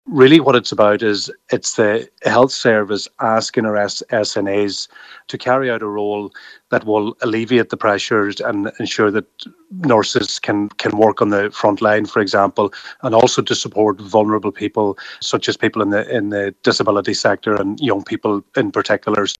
Minister Joe McHugh says their experience and skills can be put to good use……..